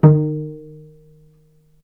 vc_pz-D#3-ff.AIF